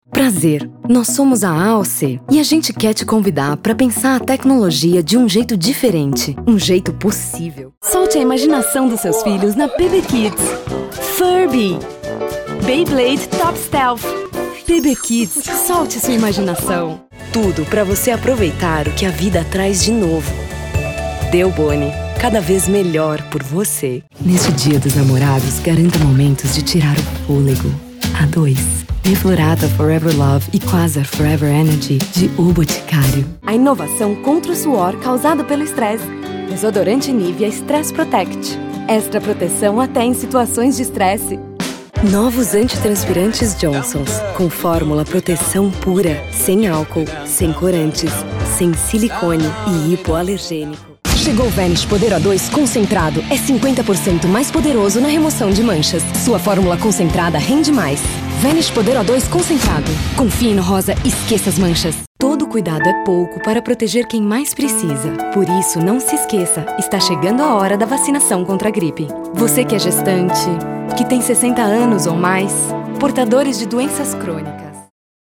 Feminino
Locução Clássica
Voz Padrão - Grave 01:15